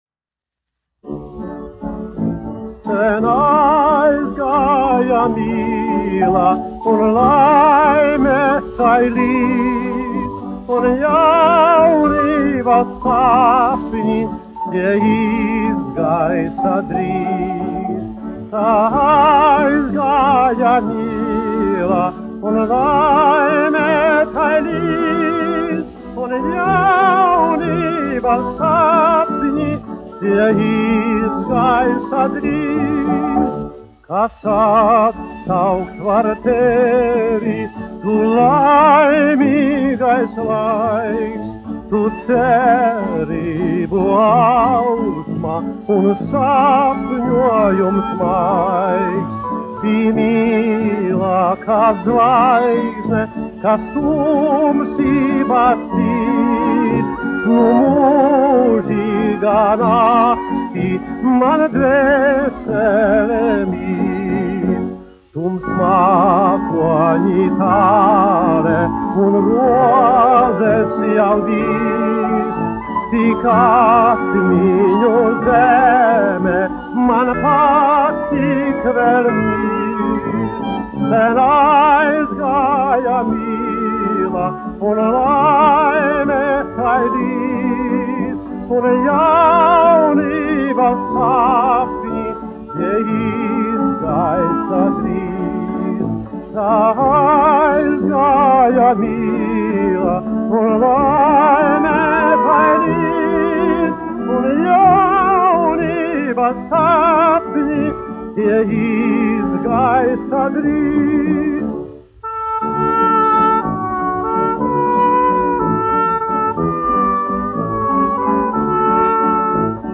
1 skpl. : analogs, 78 apgr/min, mono ; 25 cm
Populārā mūzika
20. gs. 30. gadu oriģinālās skaņuplates pārizdevums ASV